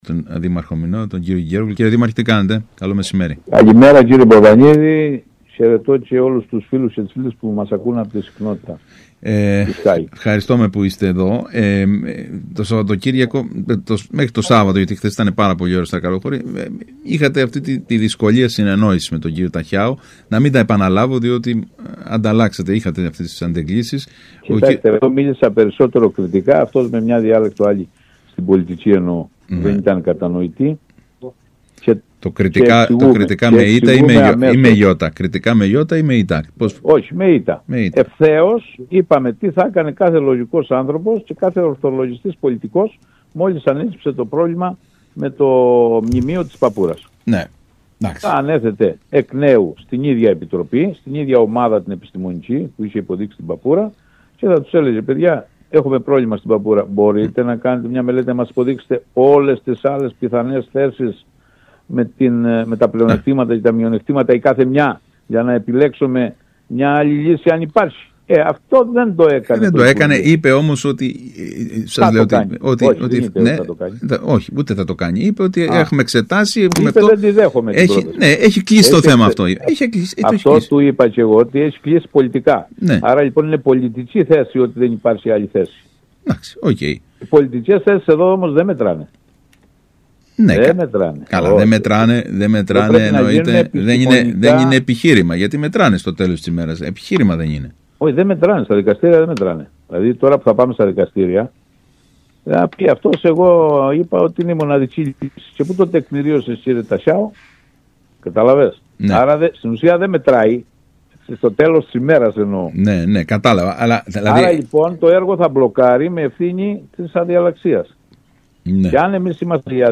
«Η απόφαση για τα ραντάρ είναι πολιτική. Δεν στηρίζεται σε τεχνοκρατικά επιχειρήματα και τα πολιτικά δεν μπορούν να σταθούν στα δικαστήρια», τόνισε μιλώντας στον ΣΚΑΪ Κρήτης ο δήμαρχος Βασίλης Κεγκέρογλου.